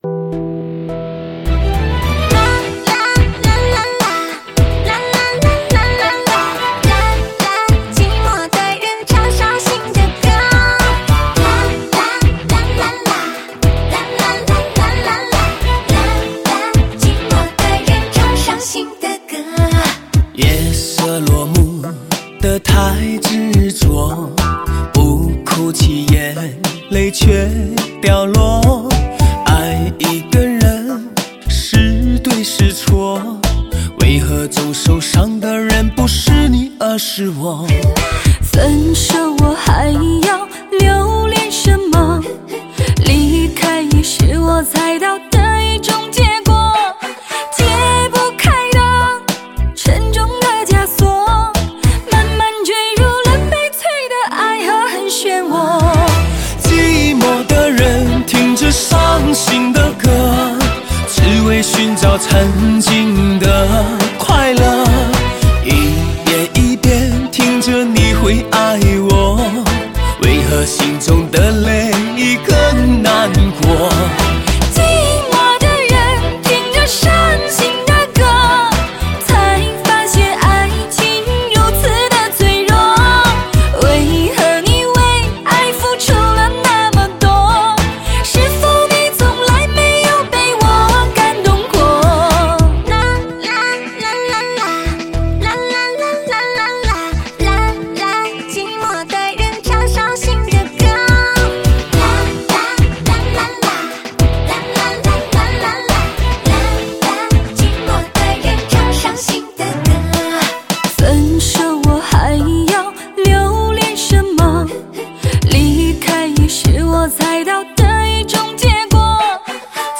顶级HIFI的声乐盛宴，唯美动听的视听享受，
贴心一路相随的暖车声音，值得珍藏的车载音乐豪礼，